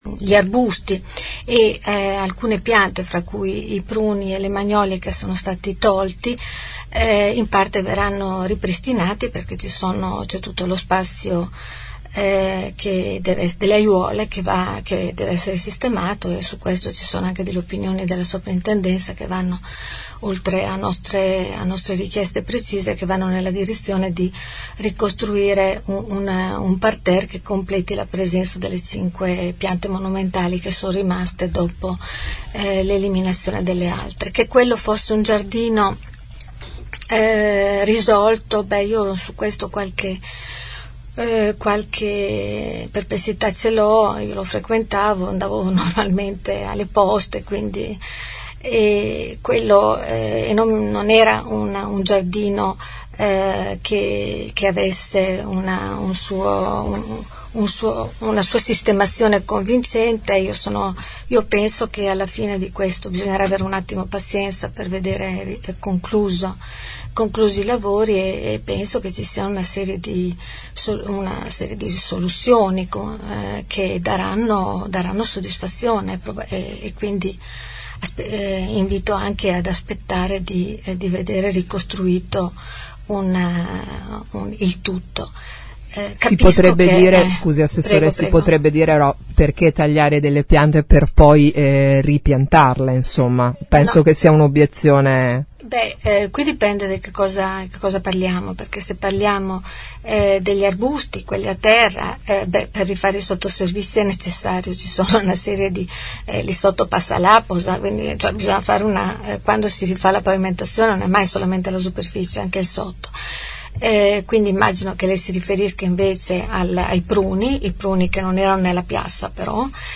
5 nov. – Patrizia Gabellini, assessore all‘urbanistica e all’ambiente, ospite questa mattina nei nostri studi, descrive così il carattere di Bologna: “è di una lentezza spaventosa, discute tanto e fa fatica a convergere, è molto attaccata al passato e ha paura“.
L’assessore ha detto ai nostri microfoni che si sta spendendo per questo.